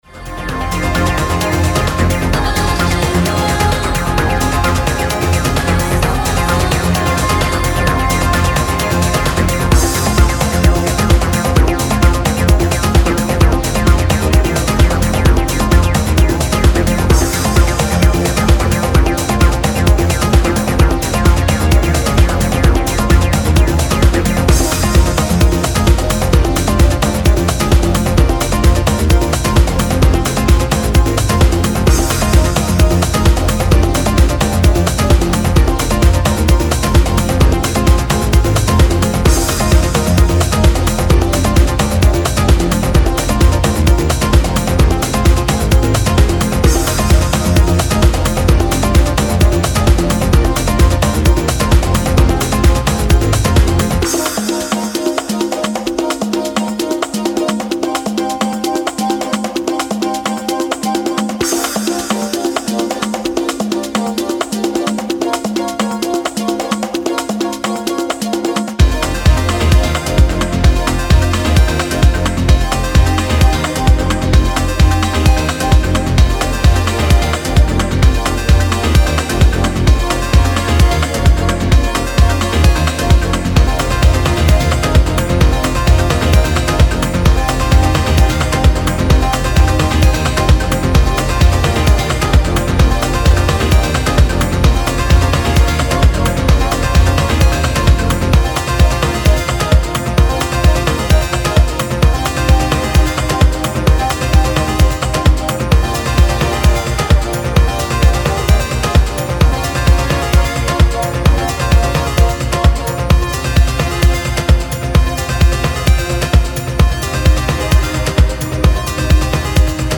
イタロ・ディスコやアーリー90sプログレッシヴ・ハウスからの影響を感じさせる絢爛なメロディーやスタブ、アシッド等を配した
極彩色で陶酔的なピークタイム・チューンを展開。